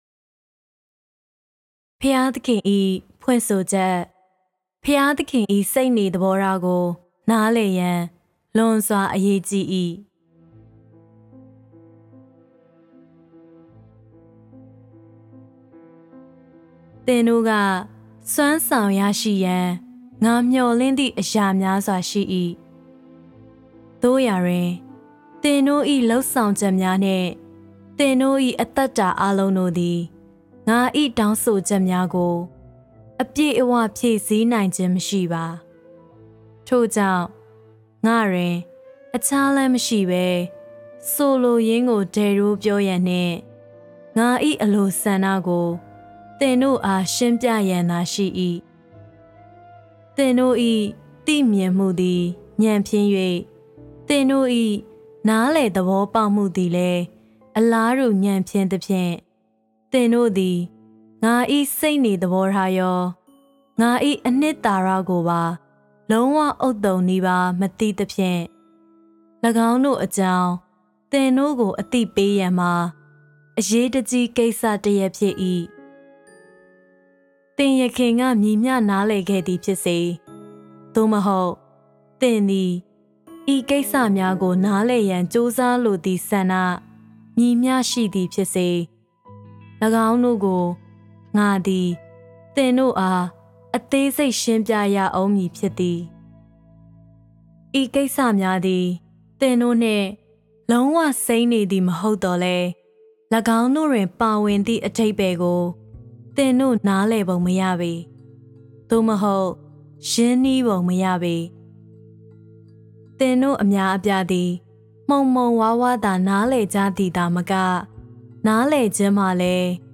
Readings